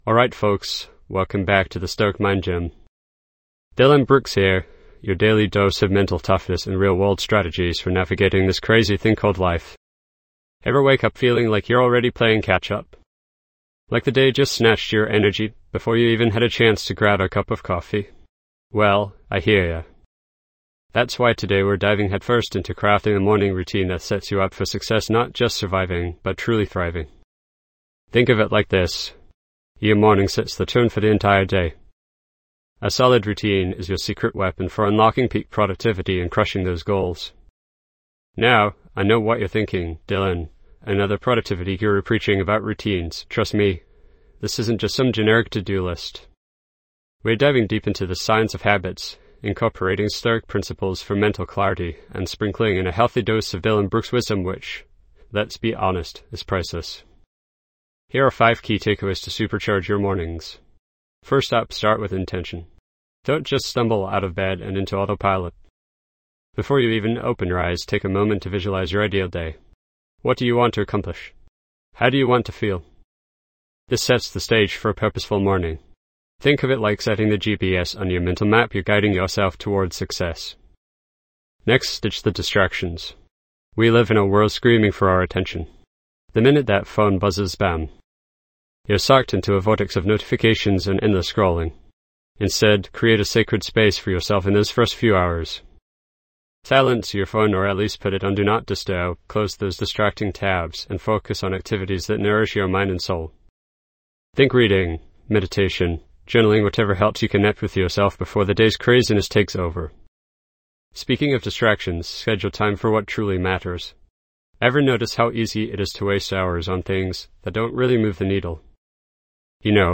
Podcast Category:. Personal Development, Self-help, Productivity, Mindfulness, Inspirational Talks
This podcast is created with the help of advanced AI to deliver thoughtful affirmations and positive messages just for you.